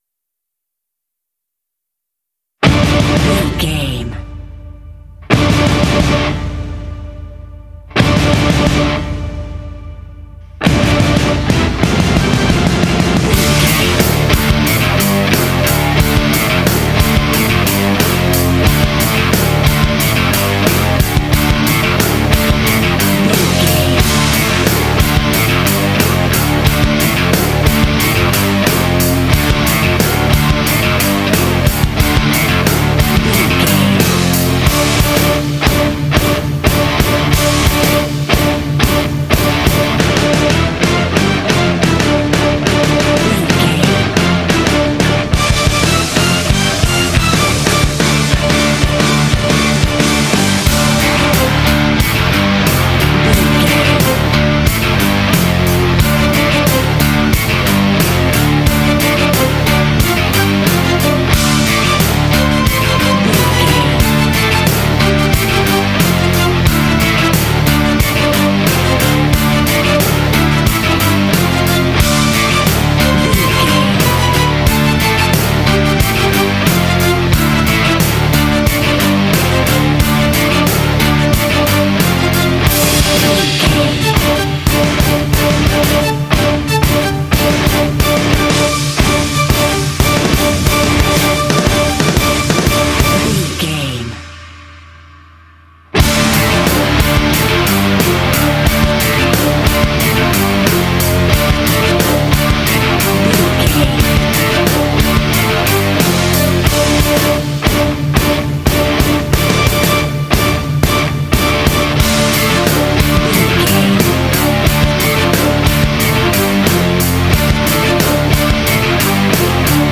Aeolian/Minor
intense
groovy
driving
aggressive
drums
electric guitar
bass guitar
strings
symphonic rock